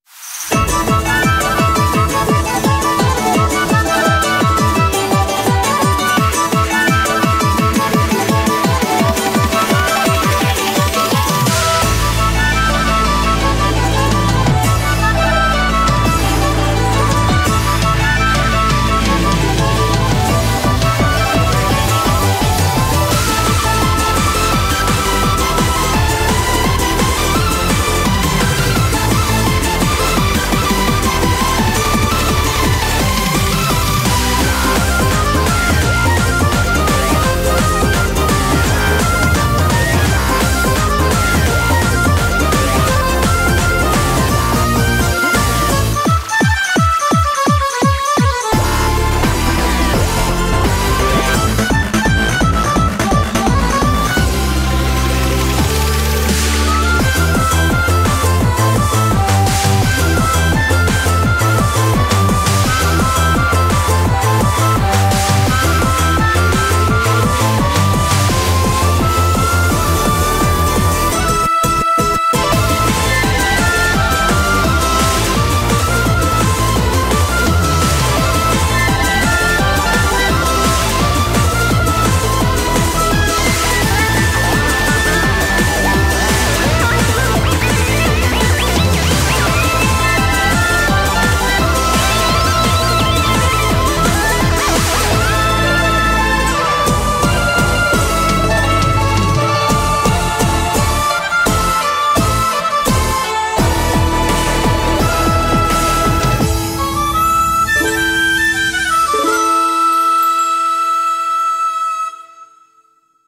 BPM170
MP3 QualityMusic Cut